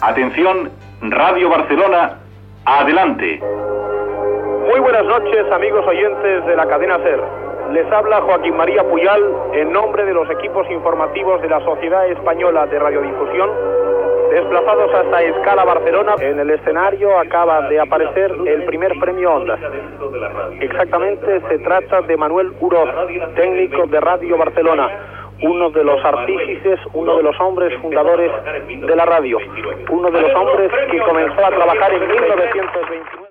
Retorn de la connexió als estudis de la Cadena SER a Madrrid.
Informatiu
Presentador/a